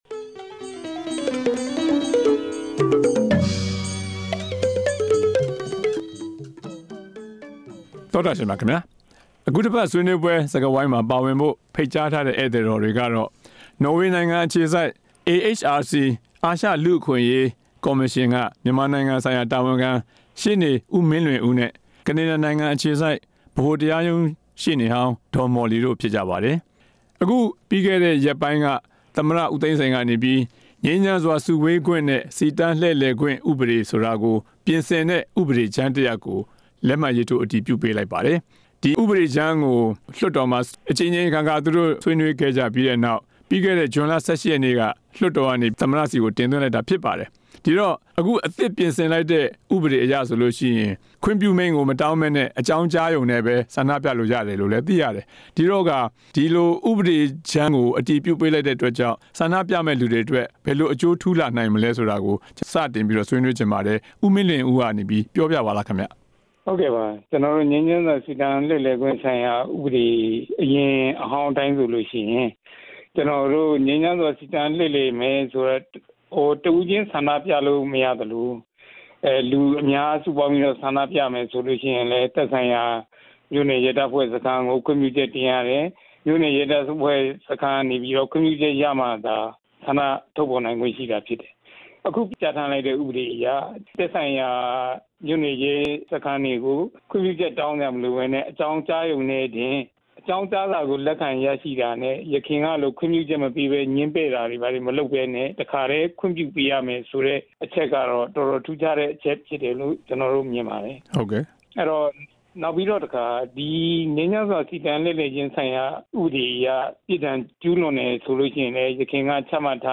ငြိမ်း-စု-စီ ဥပဒေ ပြင်ဆင်ချက် အကြောင်း ဆွေးနွေးချက်